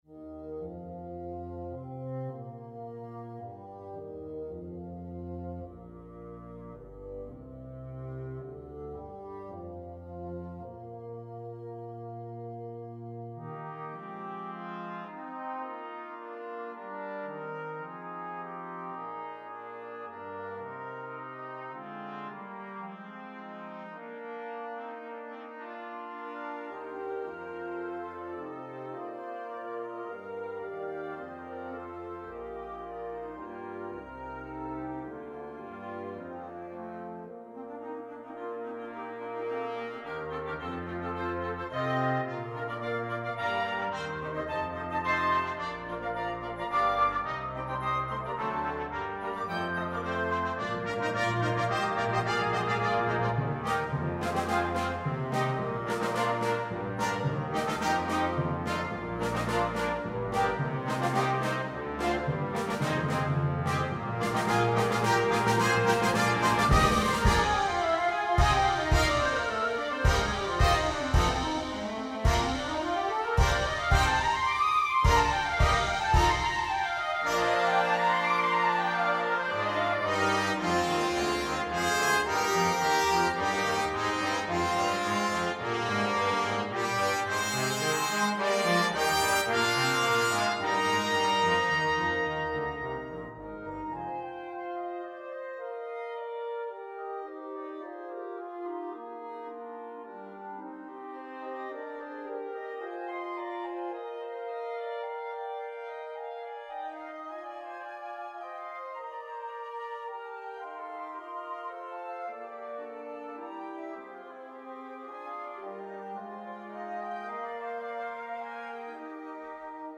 Holst: First Suite in Eb for Military Band - Midi file
FlowsFromFirst_Suite_in_Eb_for_Military_Band-OrchestralWinds.mp3